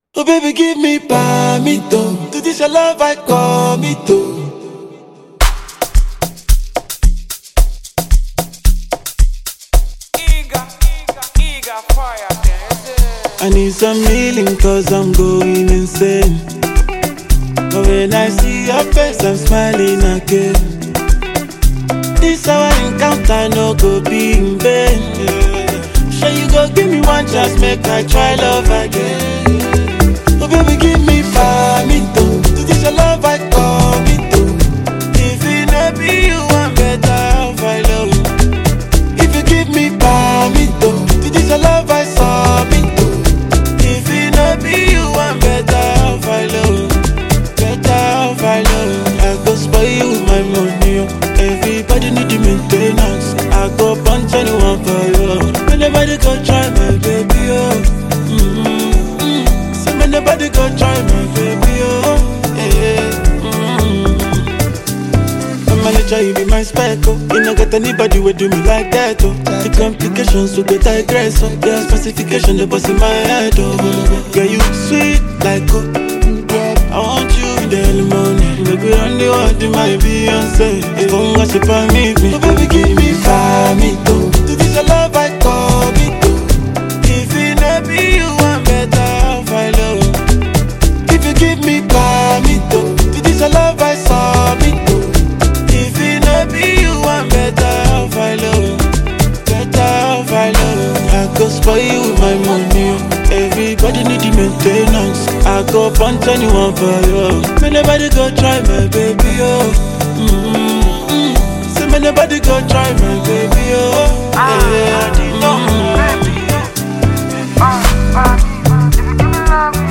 Highly talented Nigerian singer and songwriter